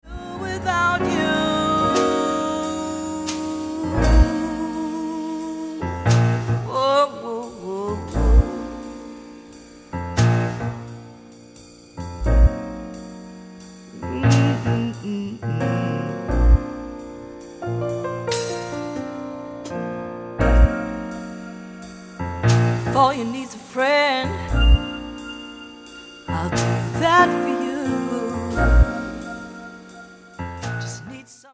nu jazz and soul tracks